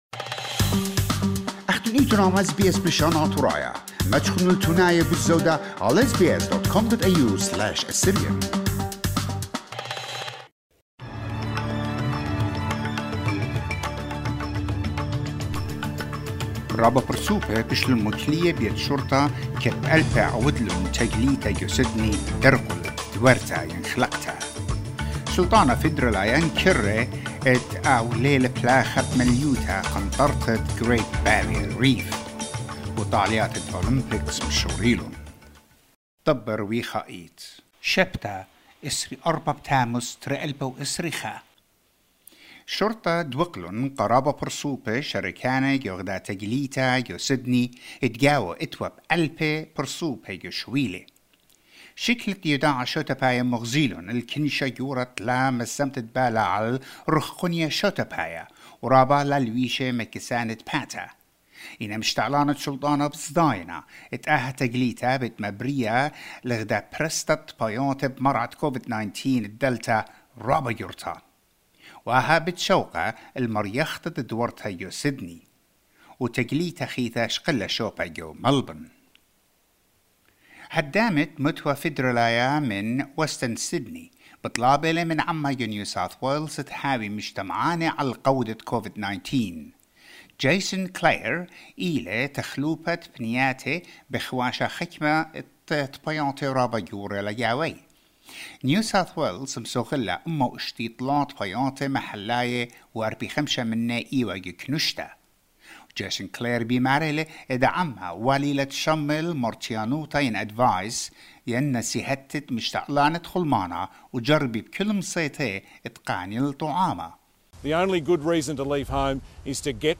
SBS NEWS IN ASSYRIAN 24 JULY 2021